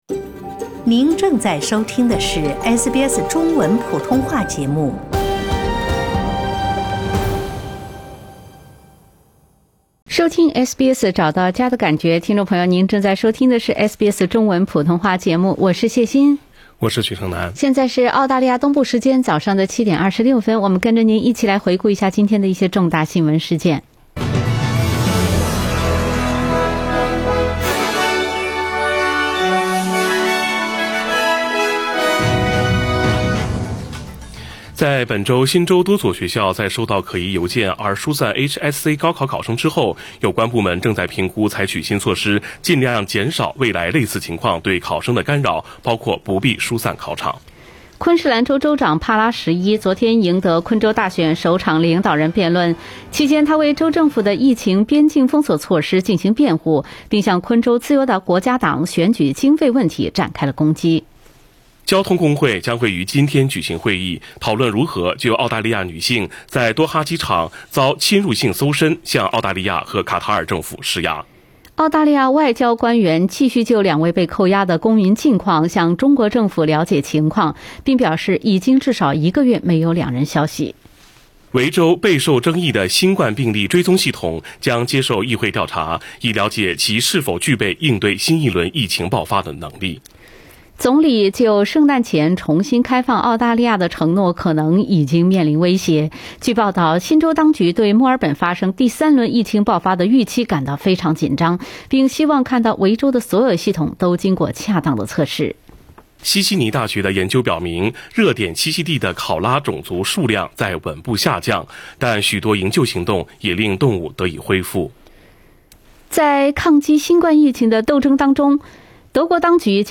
SBS早新闻（10月29日）